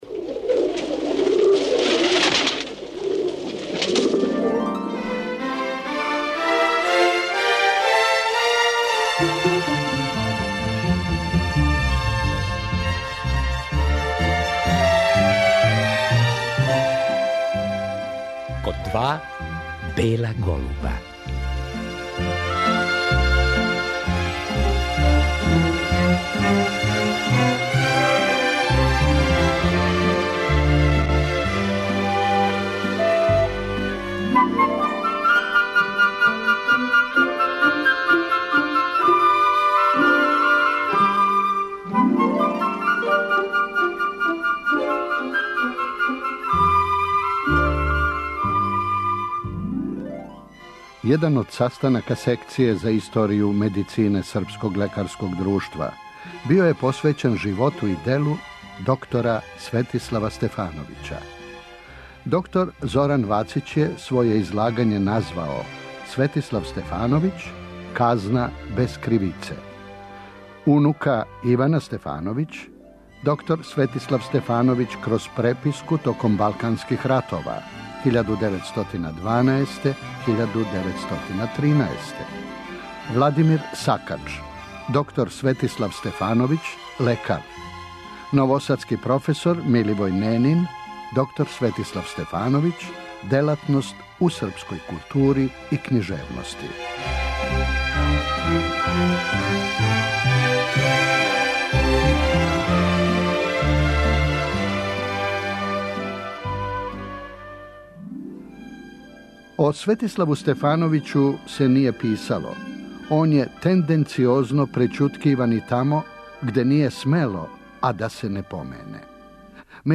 Један од састанака Секције за историју медицине Српског лекарског друштва био је посвећен животу и делу Светислава Стефановића, лекара, преводиоца и књижевника, вишегодишњег председника тог друштва.